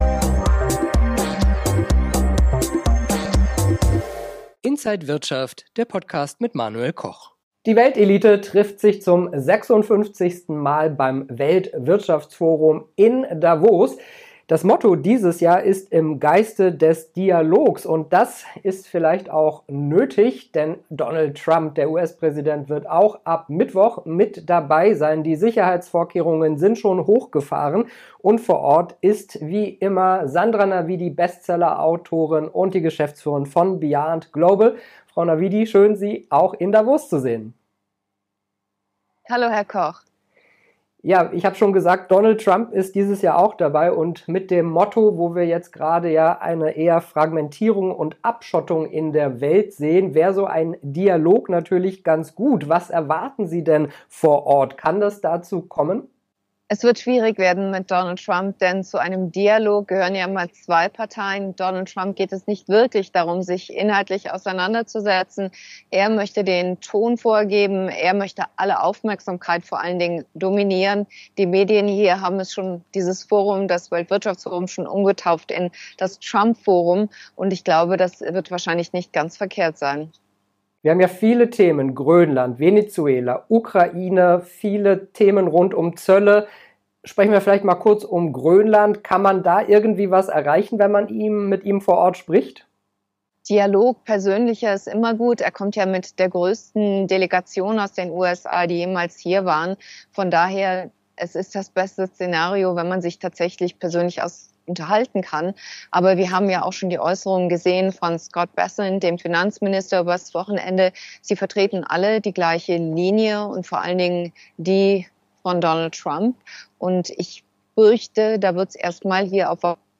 BeyondGlobal. Alle Details im Interview von Inside